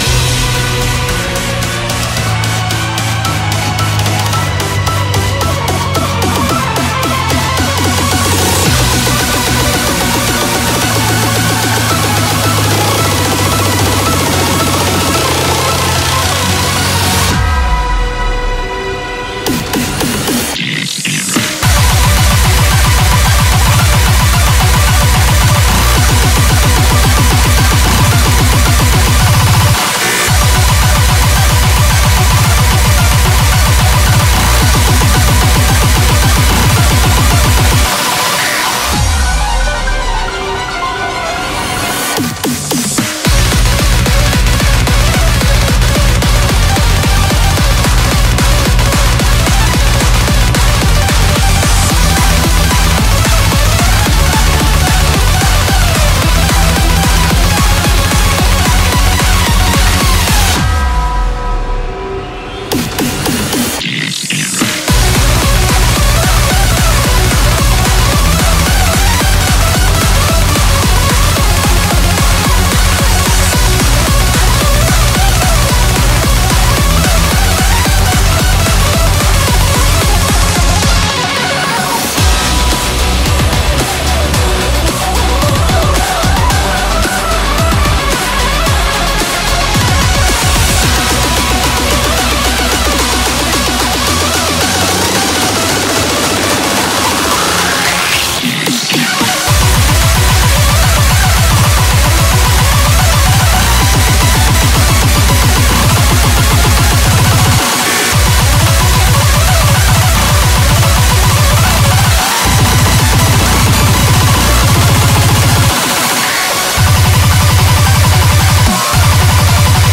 BPM111-444
Audio QualityPerfect (High Quality)
CommentairesREQUIEM SPEEDCORE